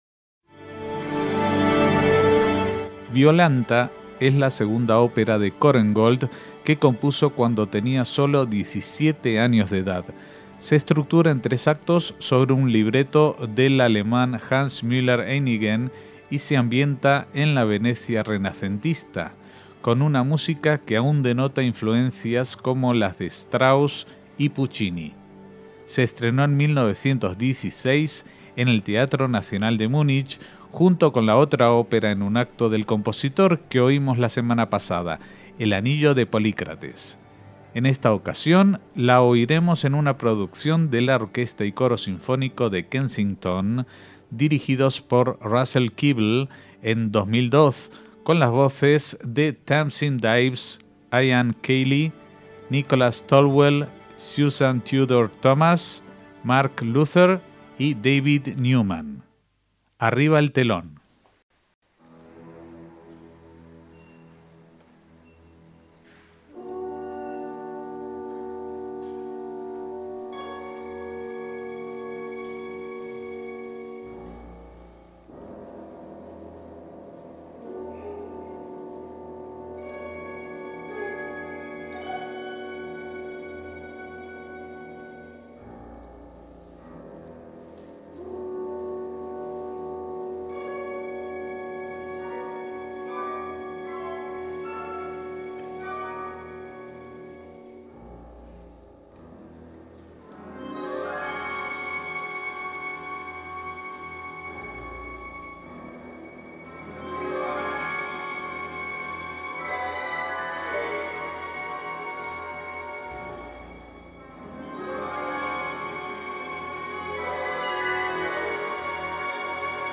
ÓPERA JUDAICA
una ópera en tres actos